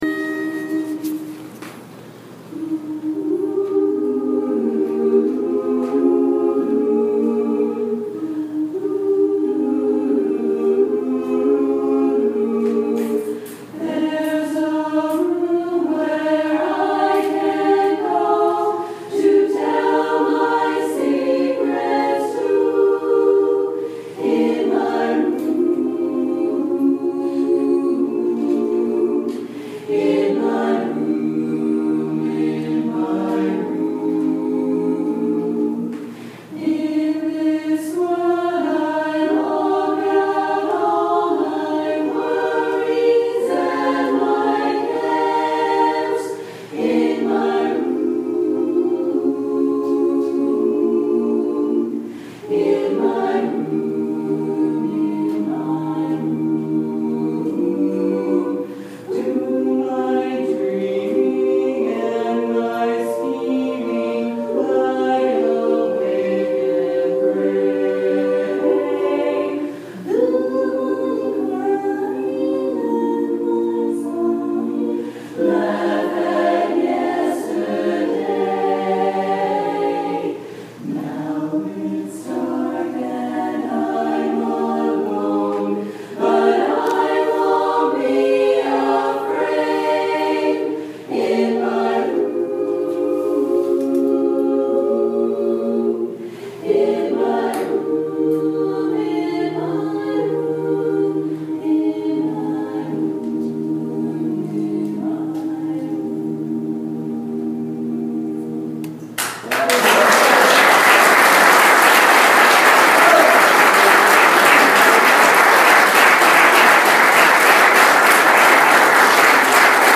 Womens Barbershop Chorus practice